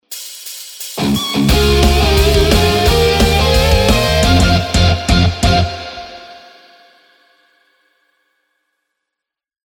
戦いに勝利したときやゲームをクリアしたときに鳴るジングルその２